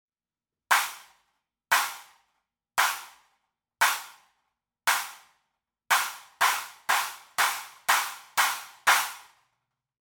Set of 3 nested / stacked cymbals 12″, 14″ and 16″.
This is our take on creating an acoustic version of a sampled clap.
We take three very thin, very warped cymbals without bells and nest them together for a unique sound.
With cymbal nut tightened: